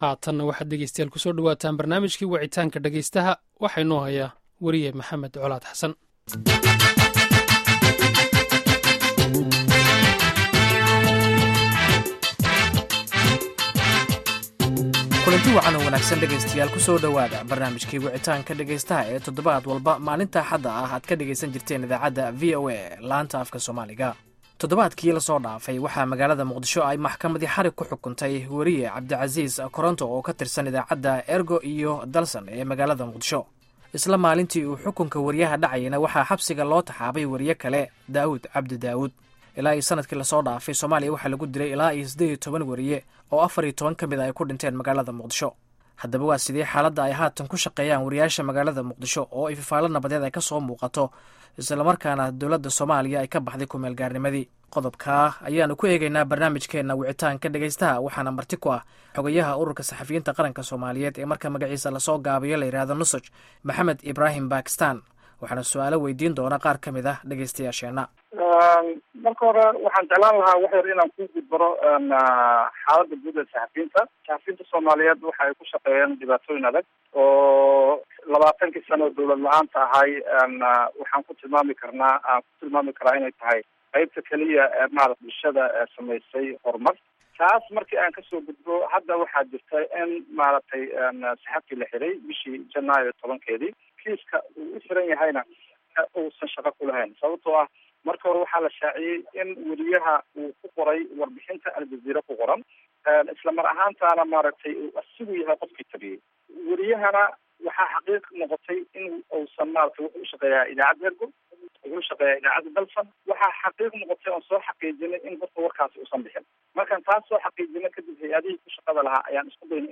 Dhageyso Barnaamijka Wicitaanka Dhageystaha